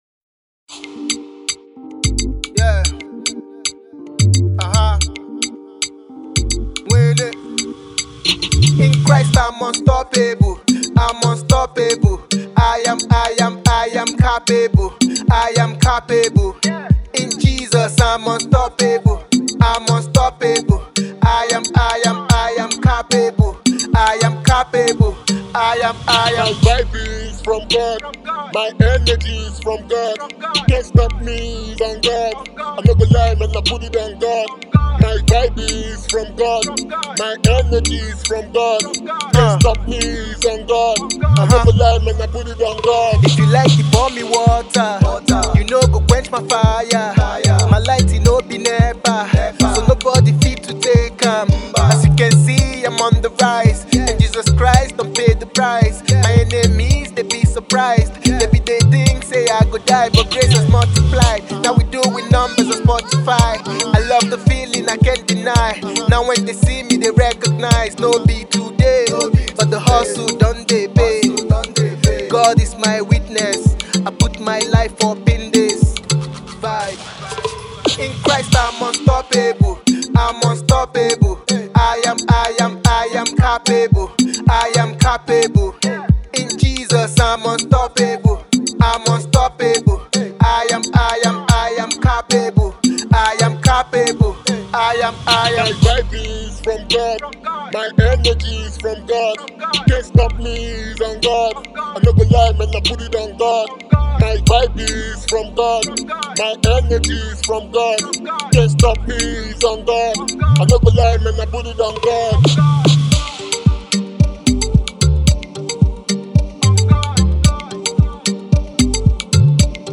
AfroGospel artiste and songwriter